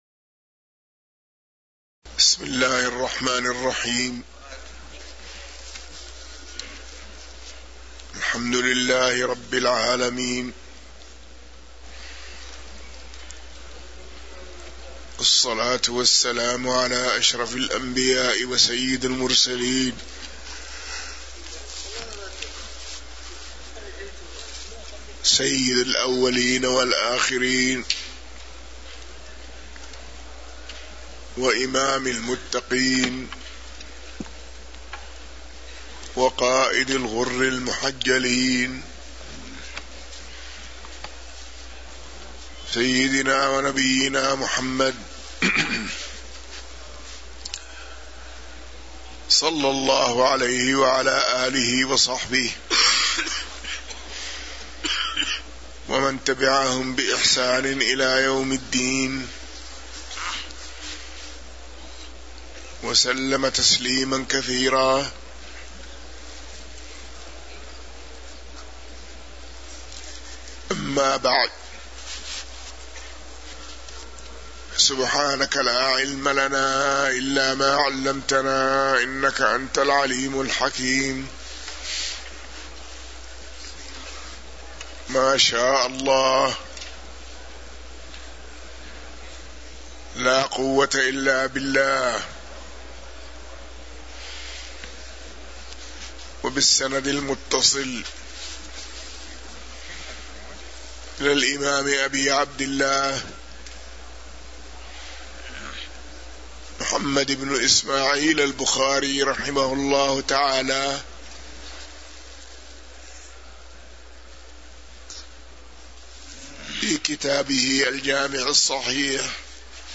تاريخ النشر ١ جمادى الآخرة ١٤٤٣ هـ المكان: المسجد النبوي الشيخ